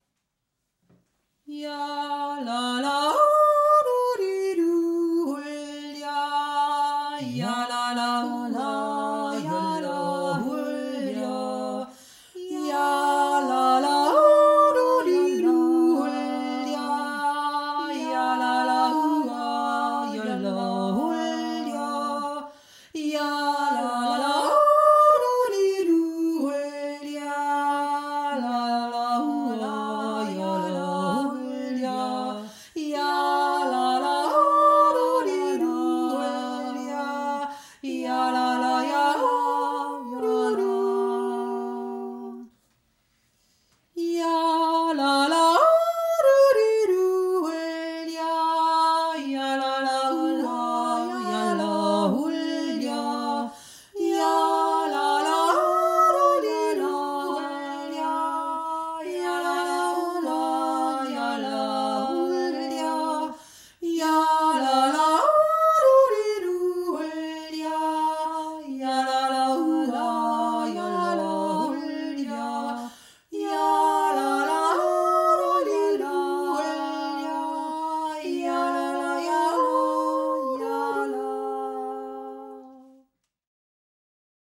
Der Jodler